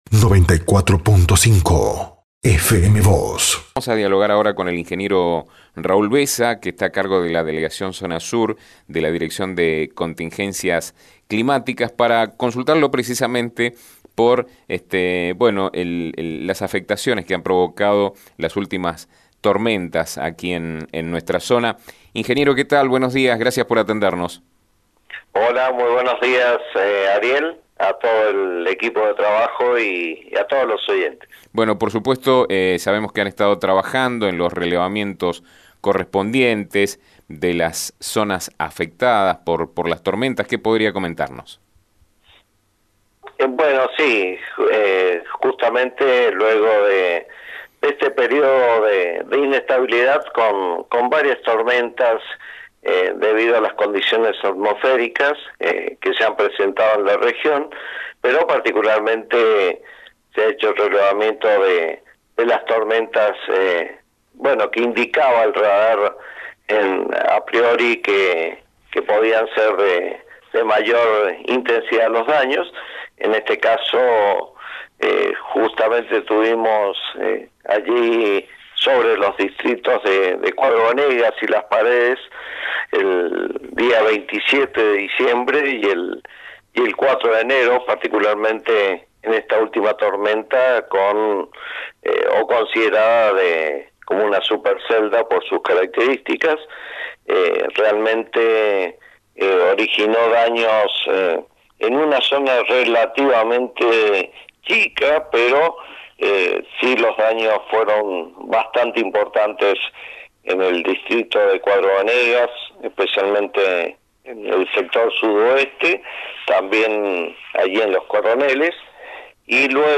se refirió a través de FM Vos (94.5) y Diario San Rafael a las afectaciones que provocaron las últimas tormentas, tras los últimos relevamientos realizados.